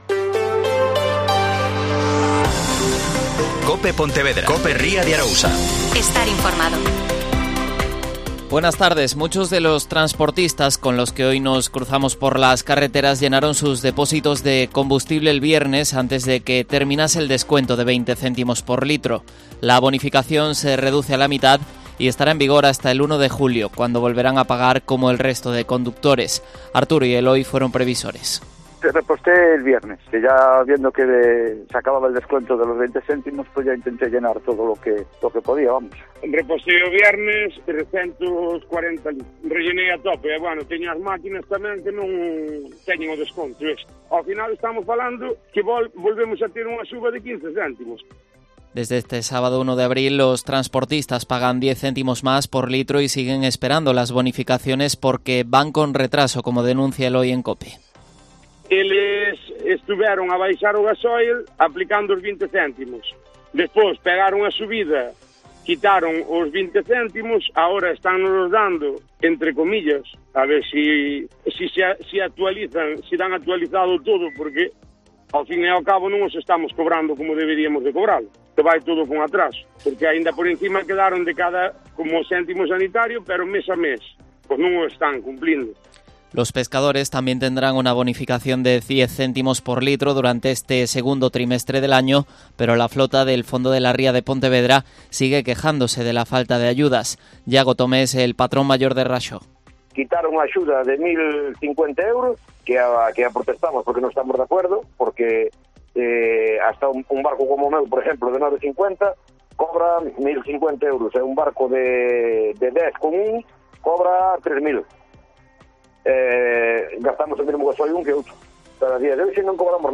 Mediodía COPE Pontevedra y COPE Ría de Arousa (Informativo 14:20h)